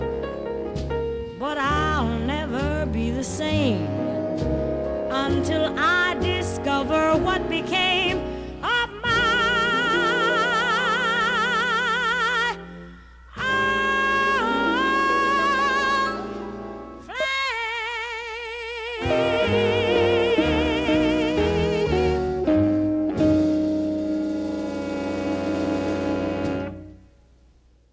here the louder her voice is the further left she goes …